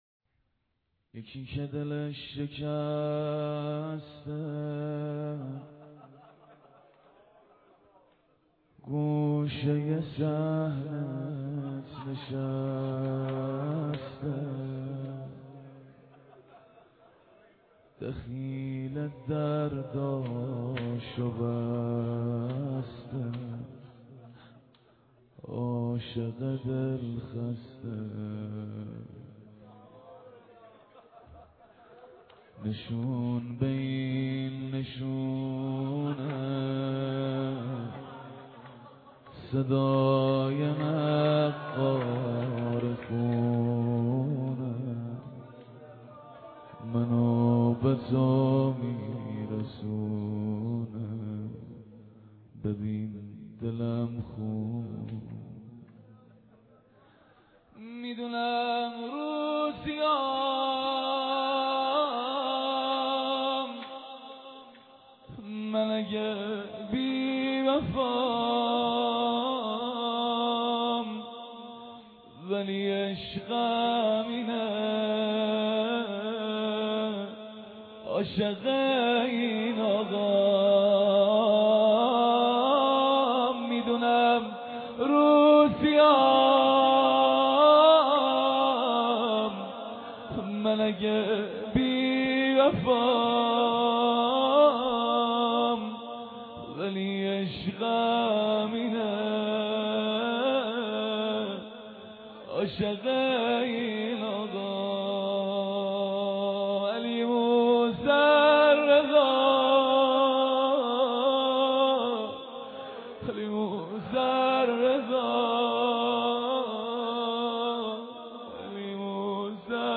مداحی حامد زمانی در هیات عشاق العباس(ع) + صوت و عکس و شعر
ولی خداییش صداش تو مداحی خیلی ضایعس ولی تو اهنگ فوق العاده هستن.
صدای حامد از نظر فنی معمولیه .